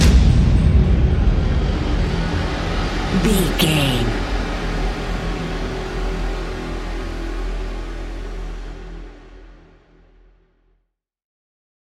In-crescendo
Thriller
Ionian/Major
F♯
industrial
dark ambient
EBM
experimental
synths